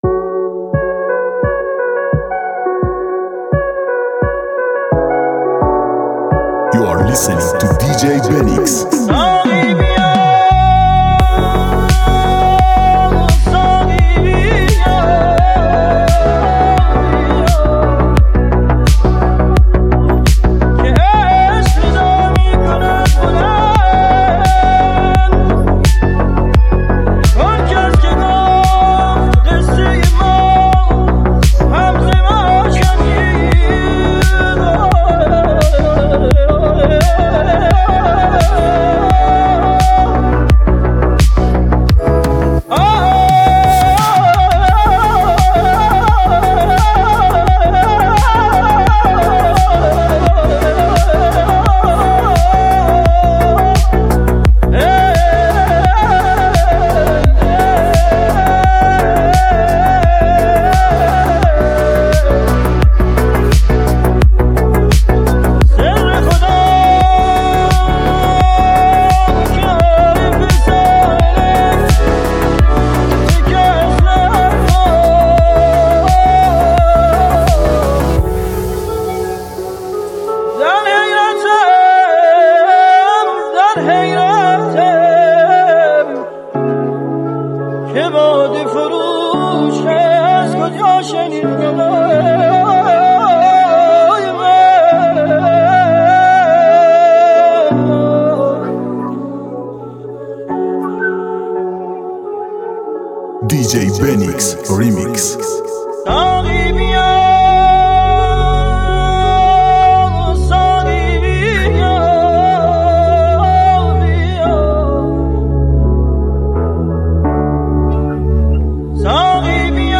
ریمیکس دوم